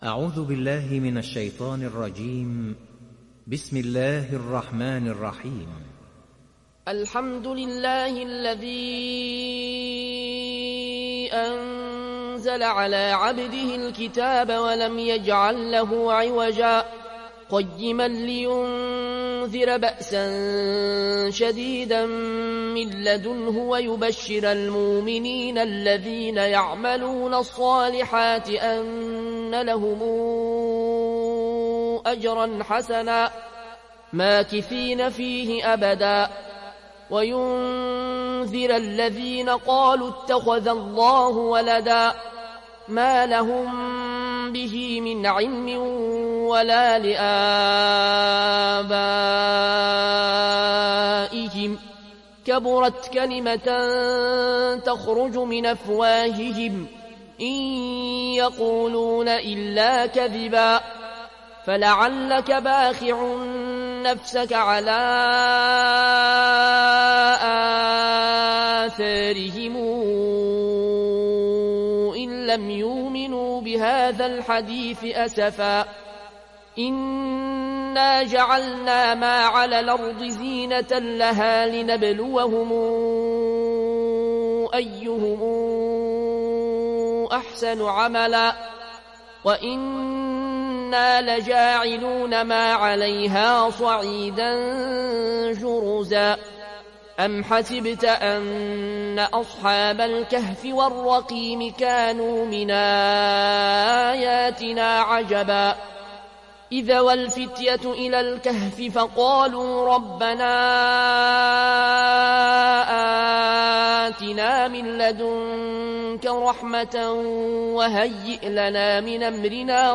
تحميل سورة الكهف mp3 العيون الكوشي (رواية ورش)